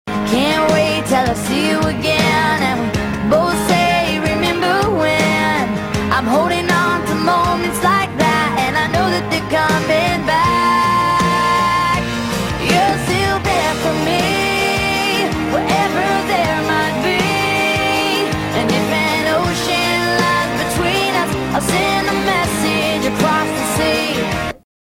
студийной записи